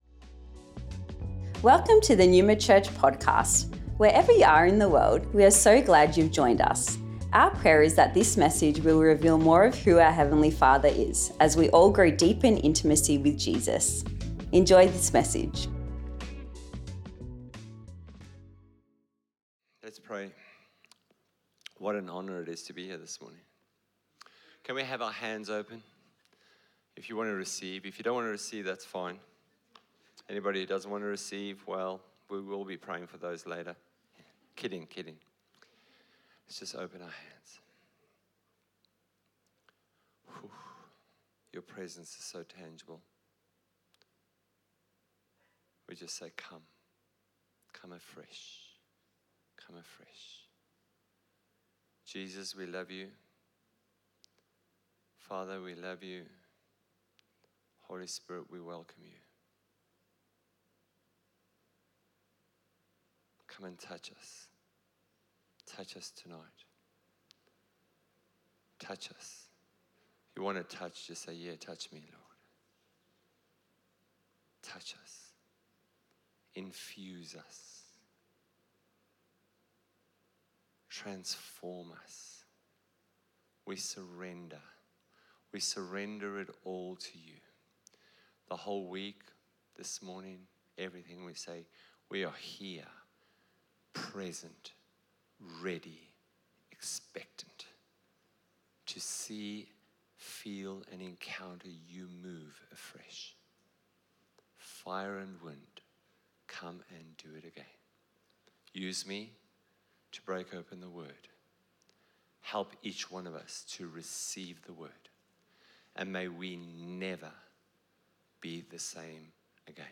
Pentecost Sunday | Empowered to Follow: The Spirit-Led Life